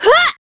Ripine-Vox_Attack4.wav